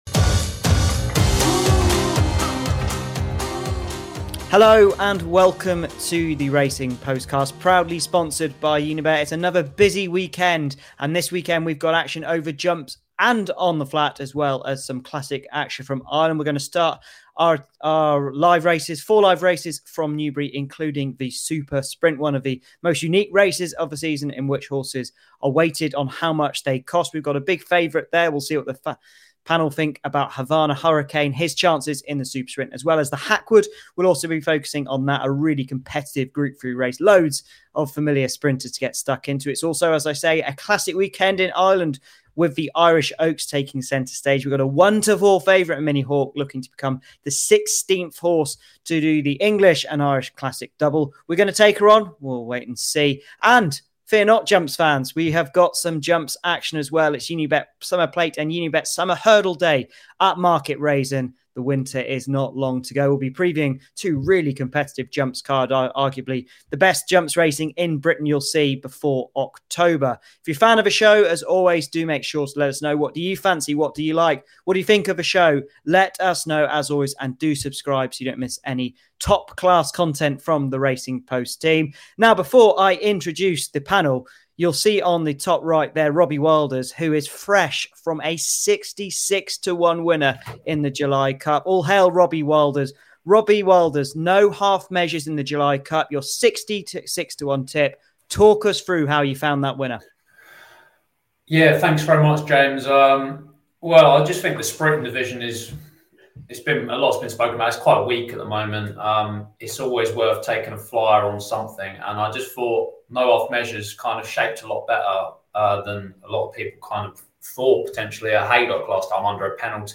Welcome to the latest episode of the Racing Postcast as our top panel preview the ITV races from Newbury, Market Rasen and the Curragh this weekend.